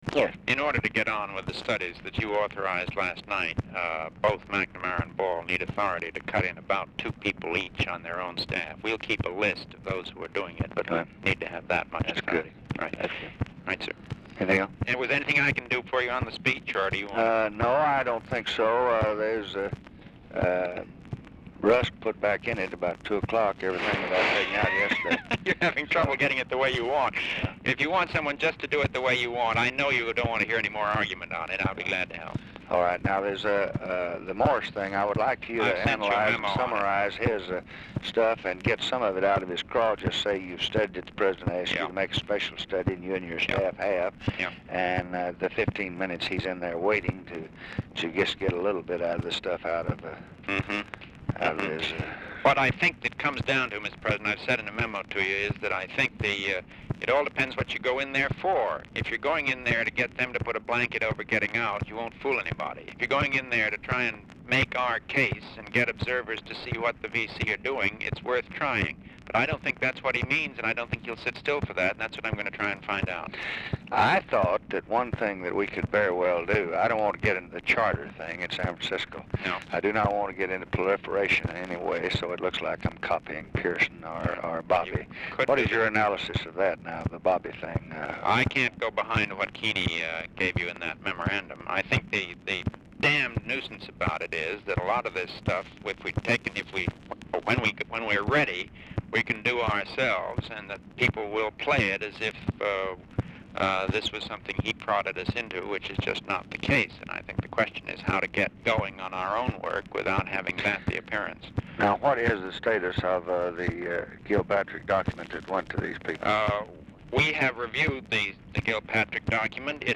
Telephone conversation # 8186, sound recording, LBJ and MCGEORGE BUNDY, 6/24/1965, 10:00AM | Discover LBJ
RECORDING STARTS AFTER CONVERSATION HAS BEGUN; CONTINUES ON NEXT RECORDING
Format Dictation belt
Location Of Speaker 1 Mansion, White House, Washington, DC